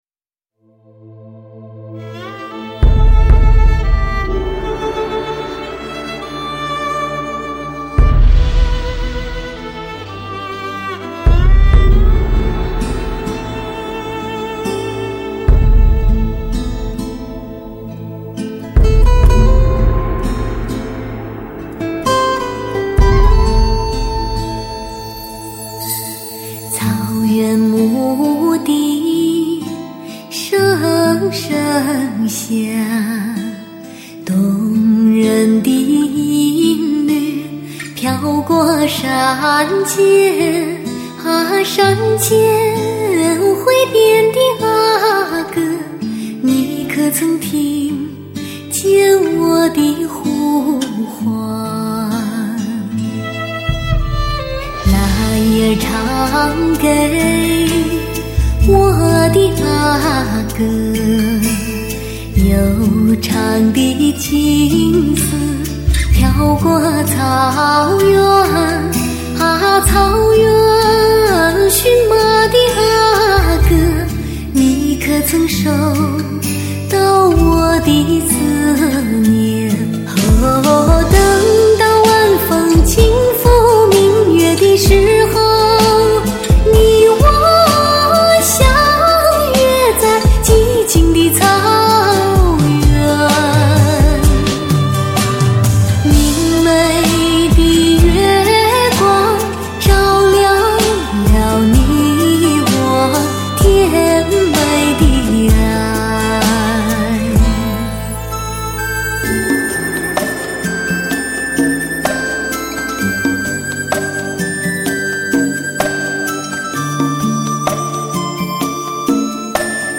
专辑格式：DTS-CD-5.1声道
圣洁的草原，绚丽的西域，宁静的草原，原生态的自然声音......
最原始的试听感受，至高境界的纯净质朴。
24K德国HD金碟，采用极品发烧级的方铜线材，真空麦克风荷电源处理器录制，